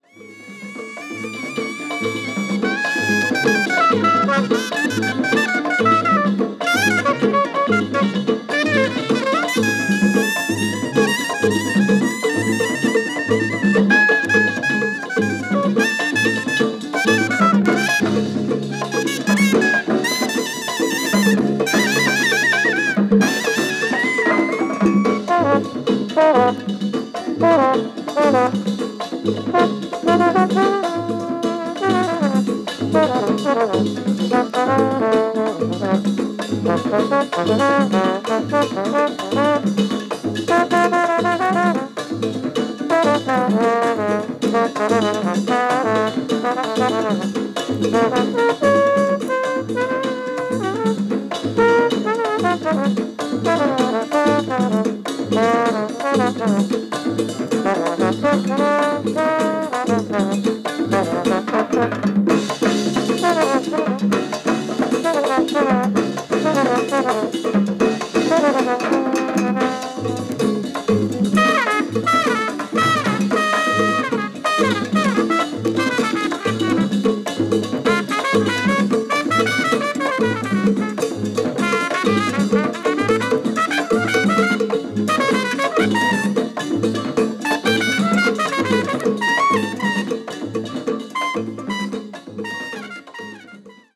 ７１年リリース・ラテンジャズ！！！
Electric Bass
Percussion
Trombone